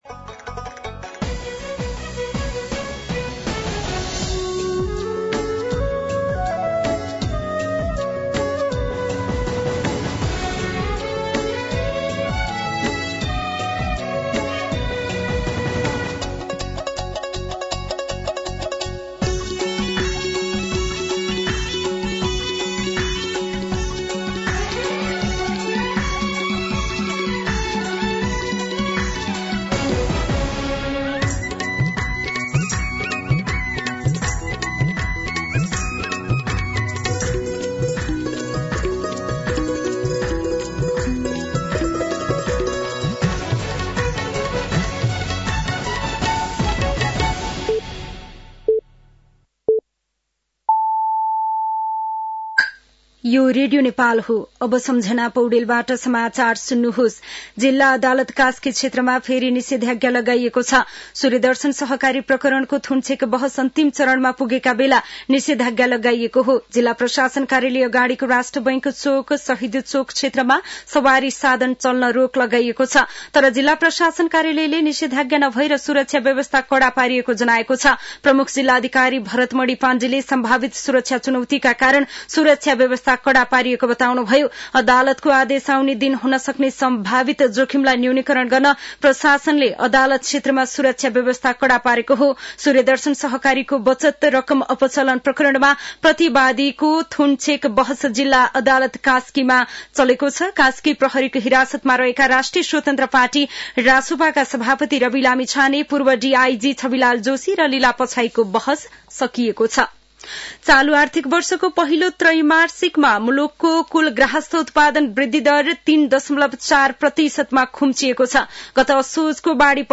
दिउँसो ४ बजेको नेपाली समाचार : २५ पुष , २०८१
4-pm-nepali-news-2.mp3